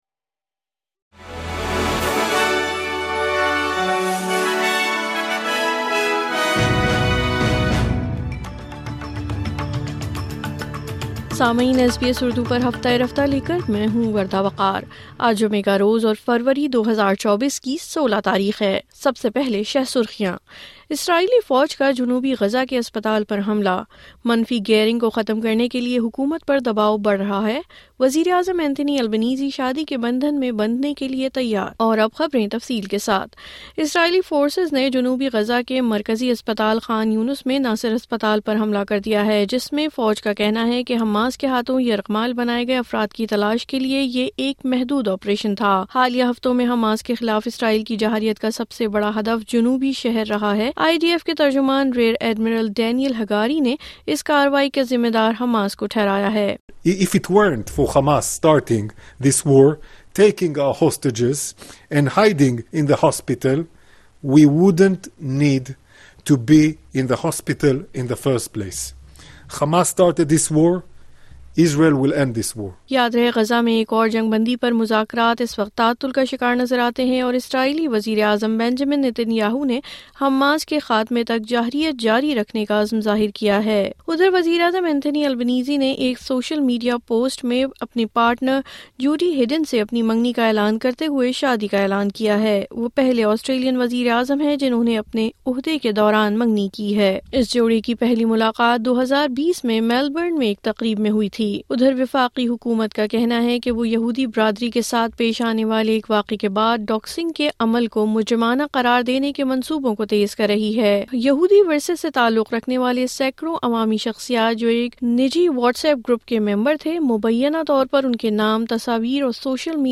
مزید تفصیل کے لئے سنئے اردو خبریں۔